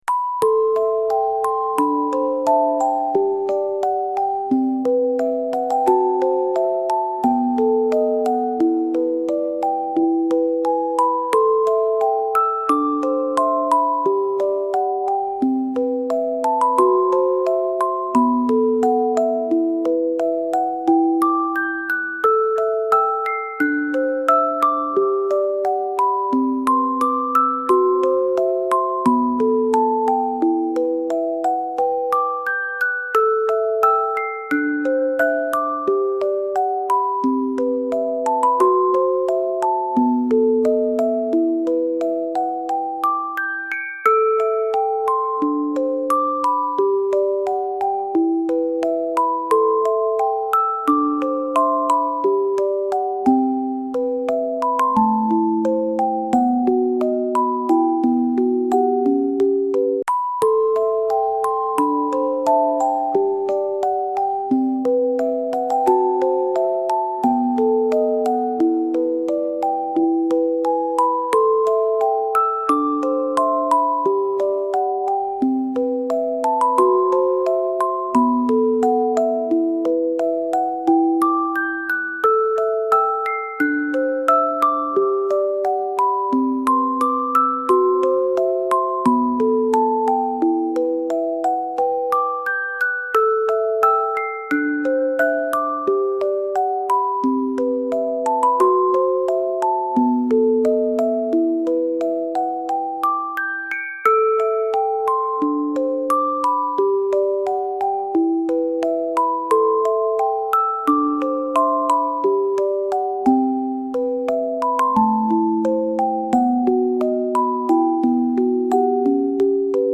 Relaxing Lullaby to Help Baby Fall Asleep Fast